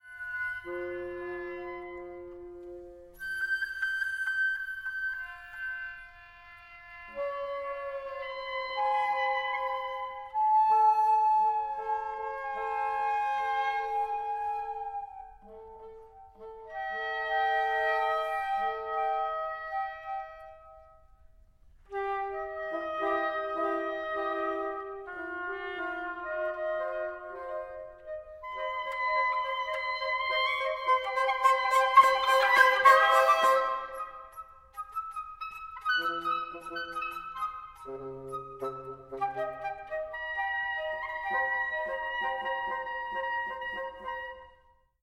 Flöte/Bassflöte (oder Klarinette), Oboe und Fagott